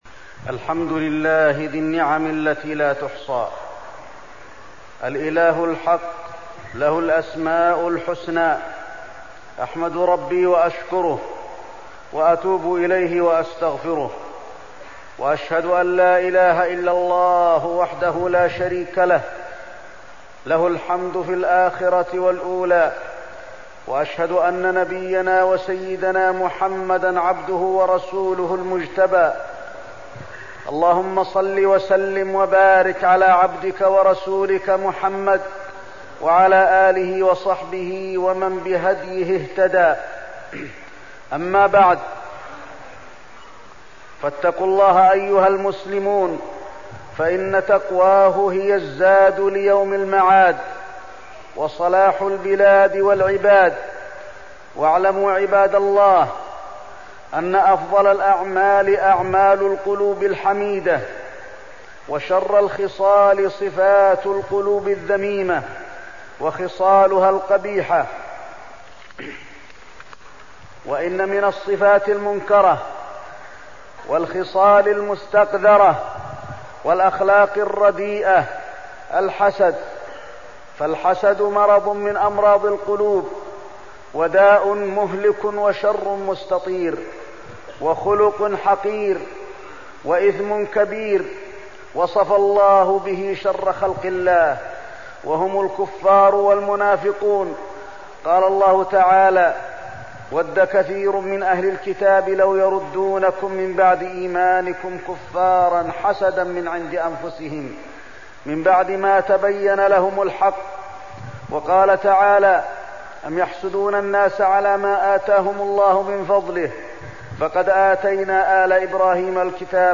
تاريخ النشر ٢٩ ربيع الأول ١٤١٦ هـ المكان: المسجد النبوي الشيخ: فضيلة الشيخ د. علي بن عبدالرحمن الحذيفي فضيلة الشيخ د. علي بن عبدالرحمن الحذيفي الحسد